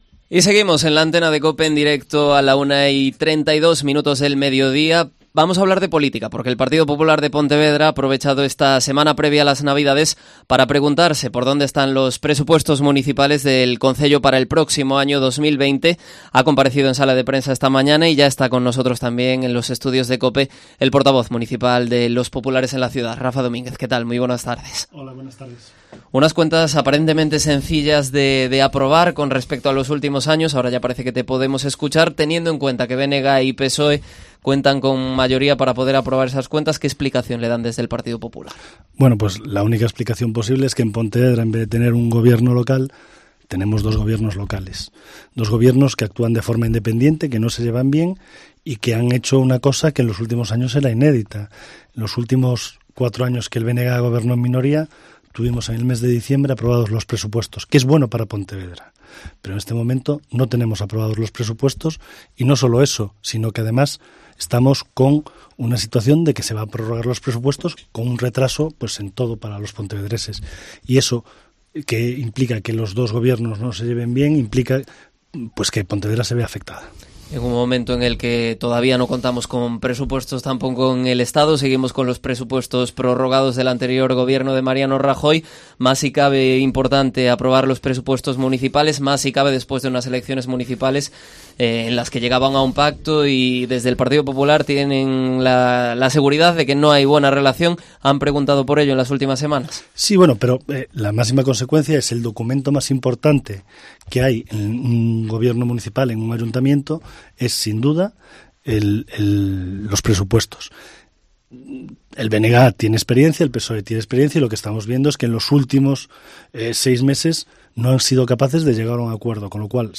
El portavoz municipal del PP, Rafa Domínguez, responde a las preguntas de Cope Pontevedra